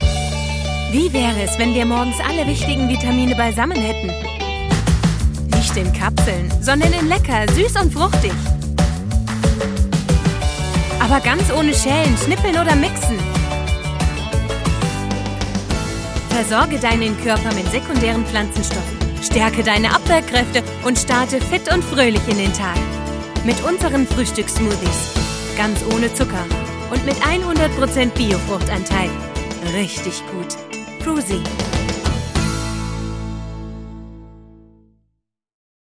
Hier ein paar Audio- und Video-Beispiele – von sinnlich, ernst oder sachlich über unbeschwert und heiter hin zu aufgebracht und verzweifelt.
Werbung & Commercials